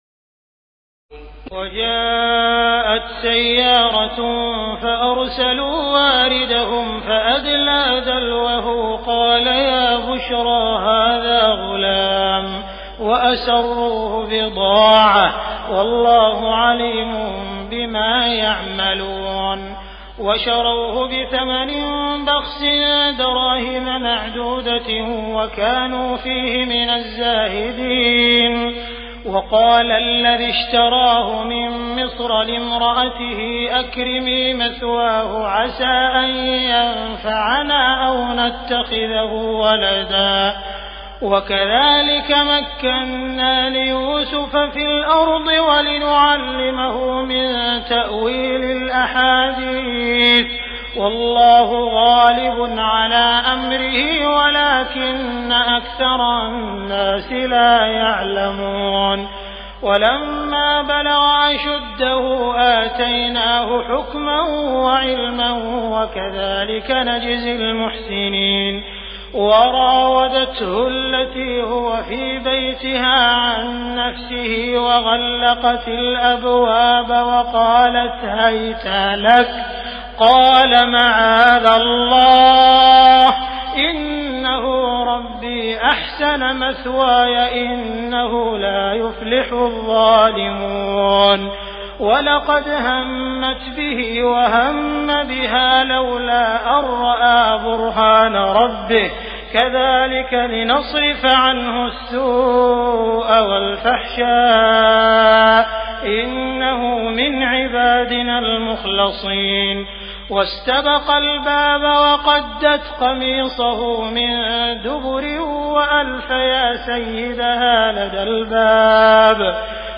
تراويح الليلة الثانية عشر رمضان 1418هـ من سورتي يوسف (19-111) و الرعد (1-6) Taraweeh 12 st night Ramadan 1418H from Surah Yusuf and Ar-Ra'd > تراويح الحرم المكي عام 1418 🕋 > التراويح - تلاوات الحرمين